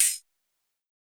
UHH_ElectroHatA_Hit-01.wav